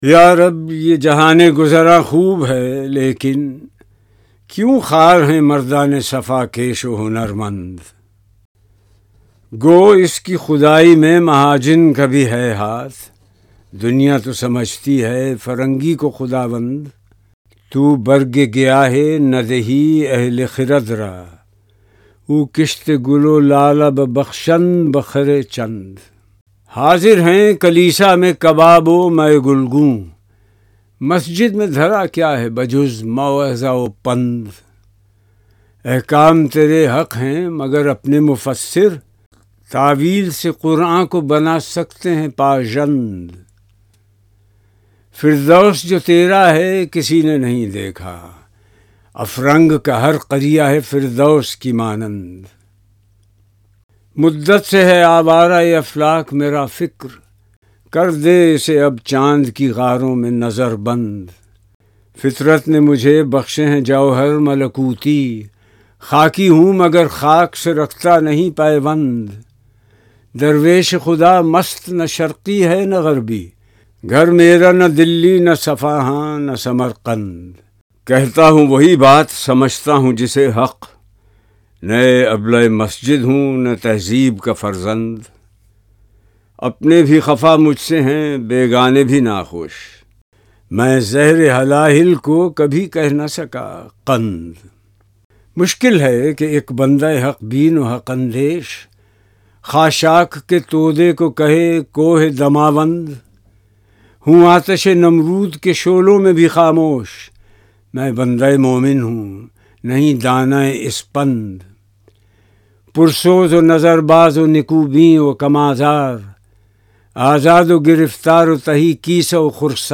Zia Muhauddin Reads Bal e Jibreel